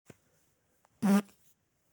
Russian Fart Sound Effect Download: Instant Soundboard Button
Russian Fart Sound Button - Free Download & Play